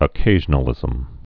(ə-kāzhə-nə-lĭzəm)